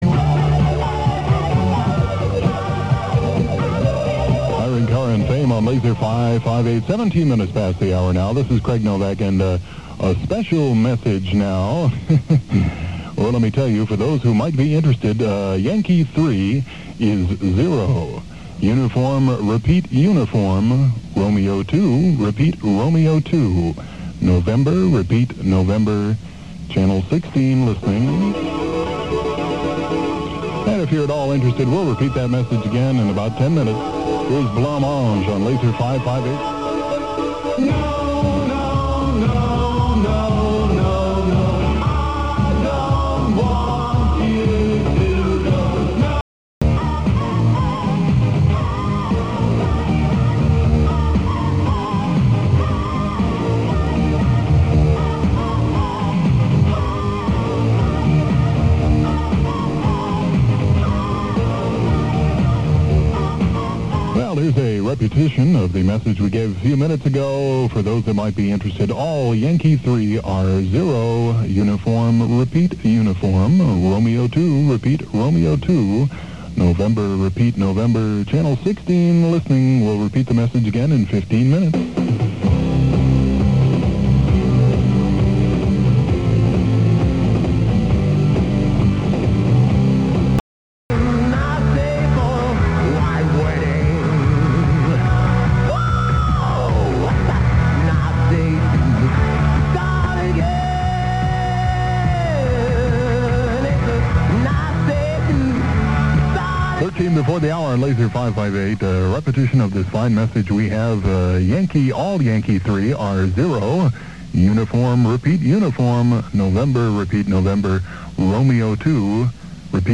Coded messages broadcast on 5th November 1985